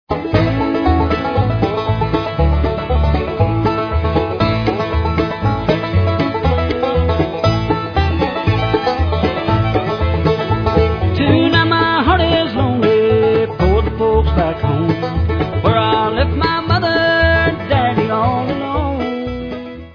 sledovat novinky v oddělení Rock/Bluegrass